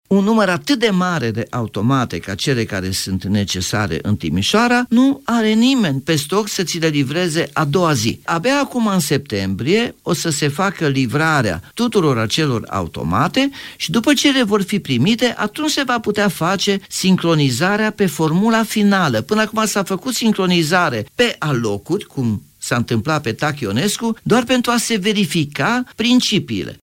Practic automatele respective trebuie înlocuite, iar dispozitivele nu existau pe stocul firmei care produce sistemul electronic, spune primarul Nicolae Robu: